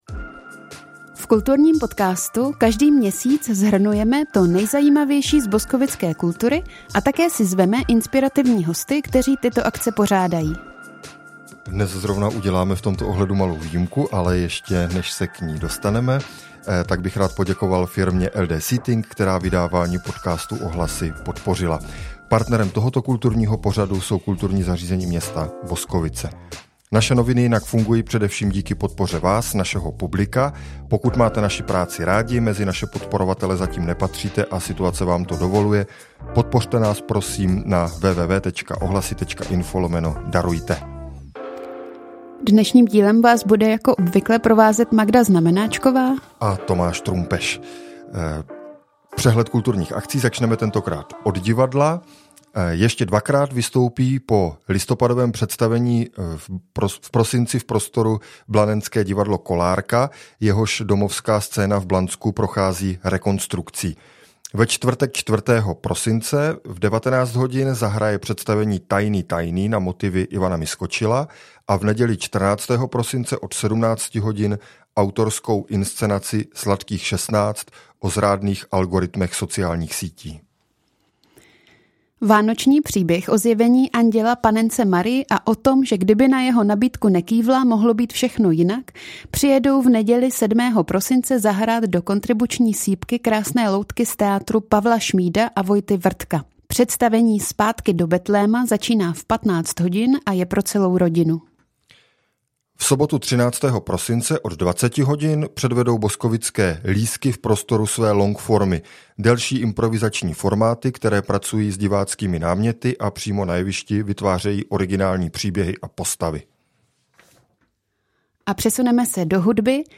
Tentokrát jsme se rozhodli pro čistě redakční debatu a zhodnotili jsme boskovický kulturní rok 2025. Součástí podcastu jsou i pozvánky na vybrané prosincové akce, ty si zde můžete také přečíst.